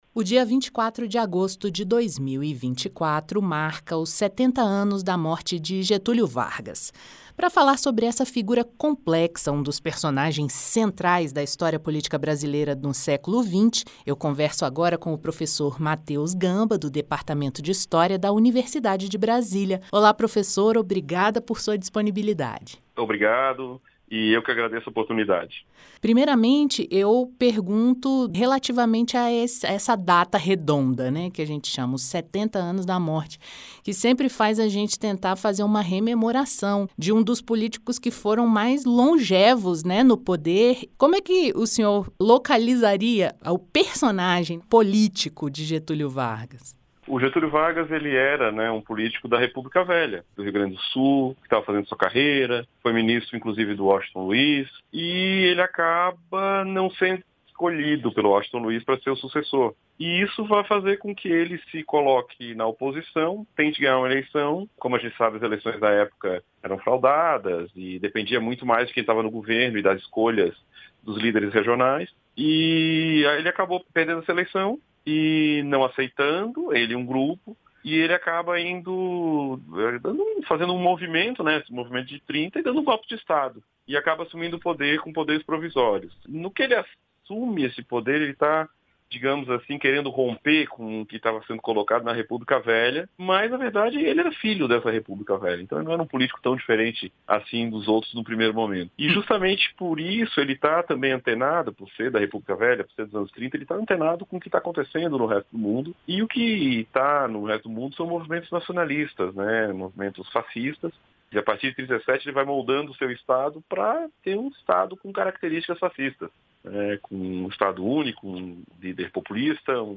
Historiador fala sobre a história e o legado político de Getúlio Vargas